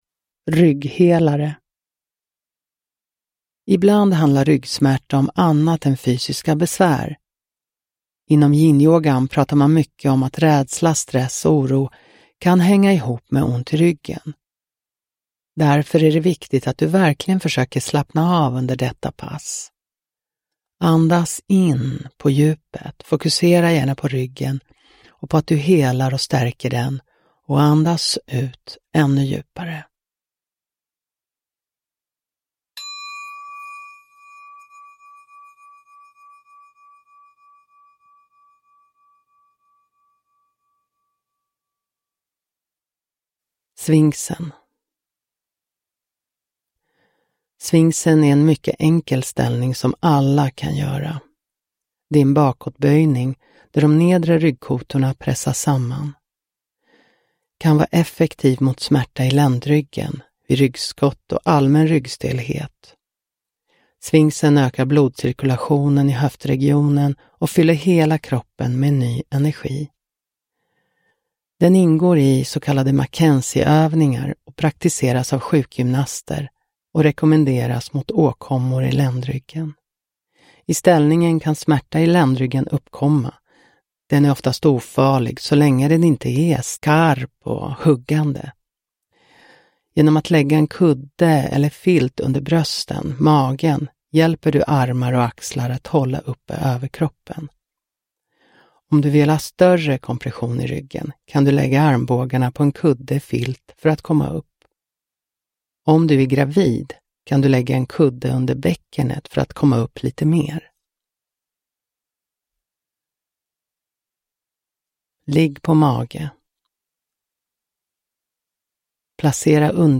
Rygghelare – Ljudbok – Laddas ner